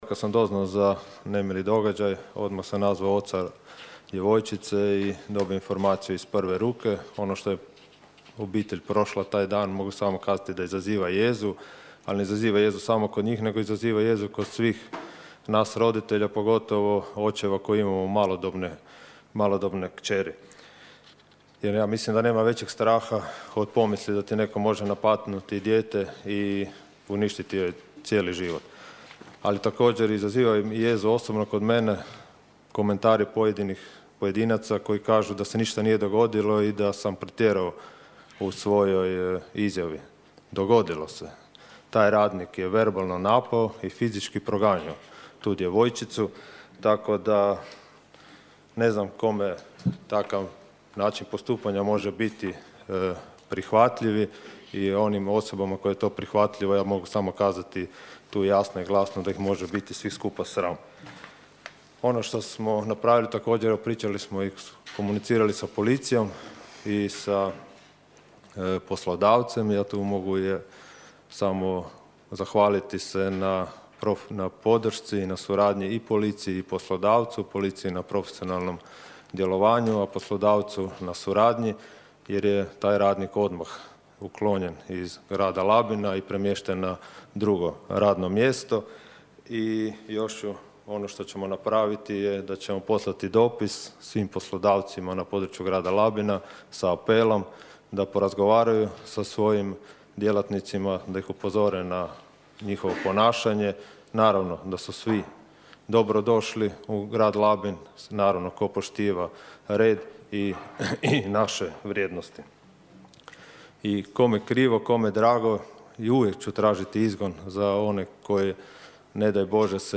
O subotnjem incidentu u Rapcu bilo je riječi i na današnjoj sjednici Gradskog vijeća Labina.
ton – Donald Blašković), odgovorio je gradonačelnik Donald Blašković.